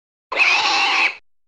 Выстрелы треснувшее стекло визг свиньи